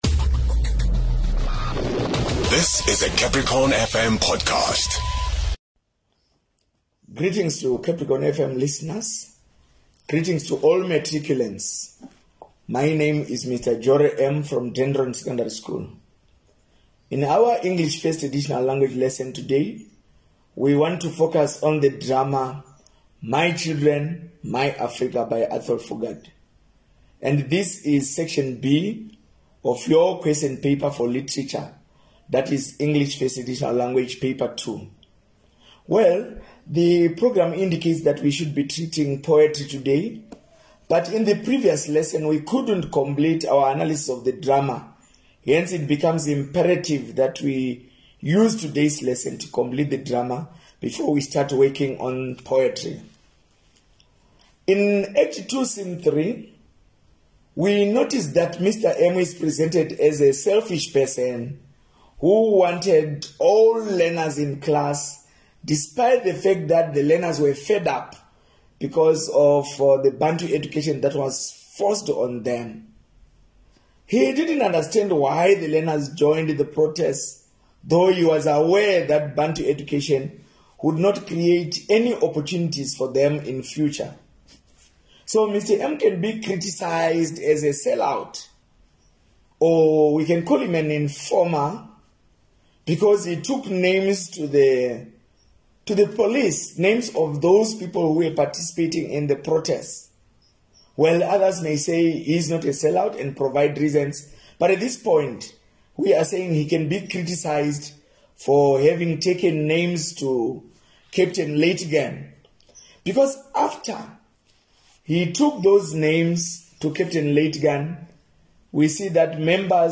17 Nov Grade 12 Lessons